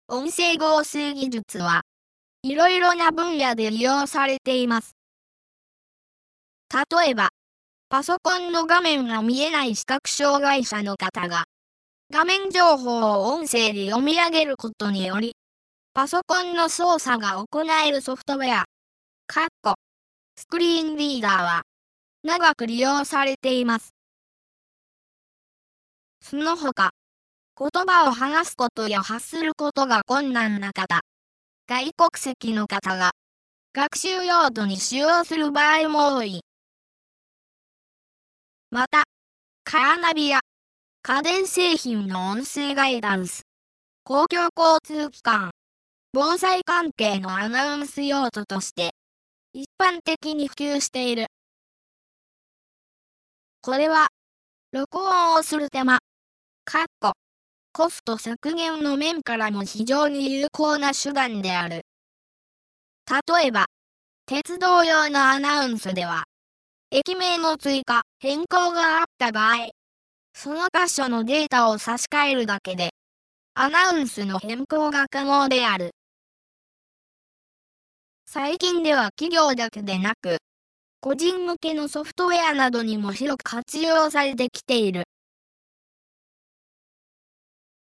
RealNarrators 3はパワーポイント(PowerPoint)を合成音声で読み上げ、スライドショー等の動画用コンテンツを作成するソフトウェアです
高品質日本語版　　（男性音声サンプル）
ケンタ二男。元気な小学生。